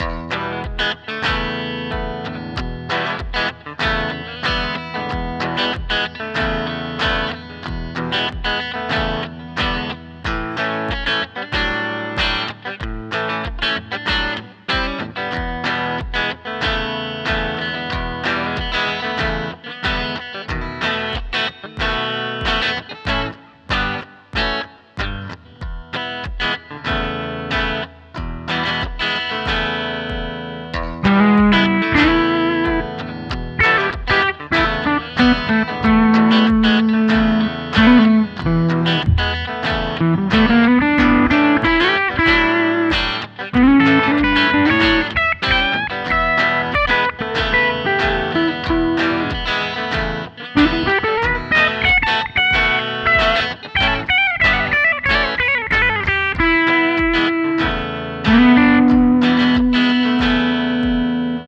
Here is something I threw together a clean-ish rhythm sound and dirtier lead.